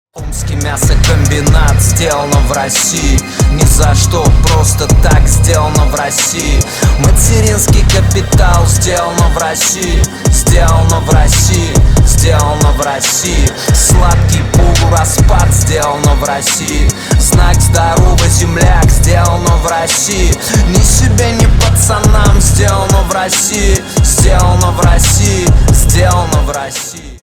• Качество: 320, Stereo
русский рэп
качающие